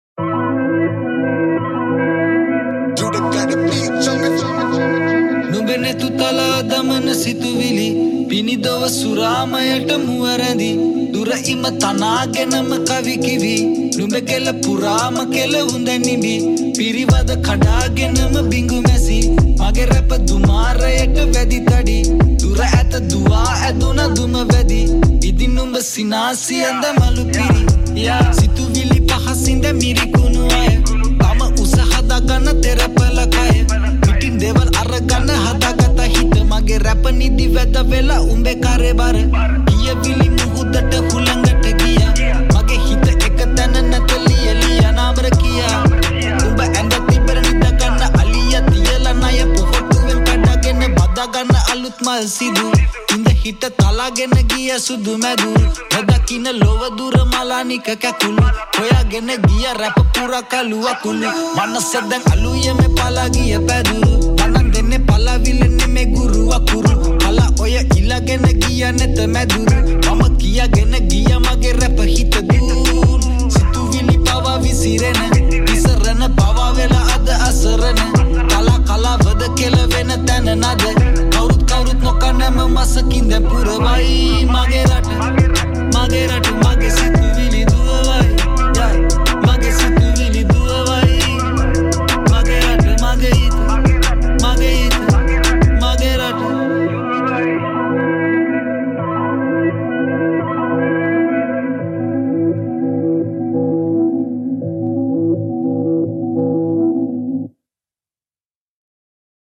remix
Rap